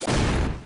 Slam.mp3